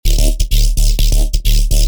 Intricate, dynamic and versatile sounds with huge character!
Wubs
Classic Bass House Wubs or UK Bass styled wub basses, we’ve got it all covered!